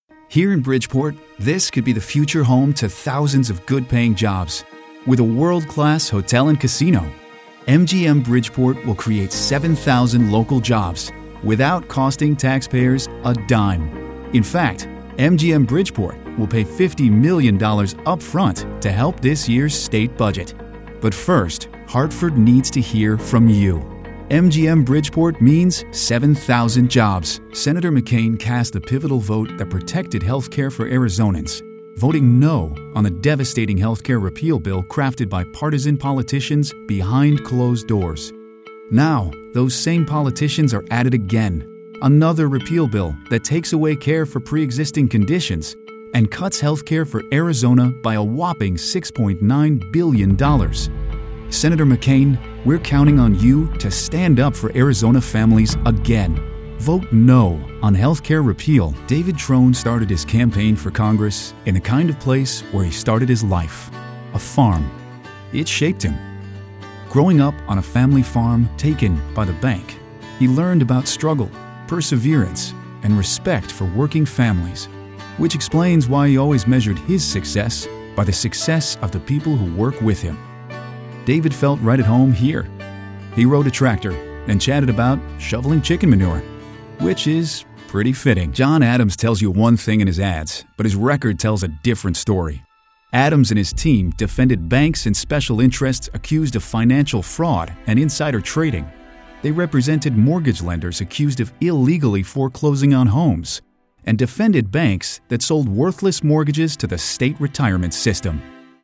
Political Demo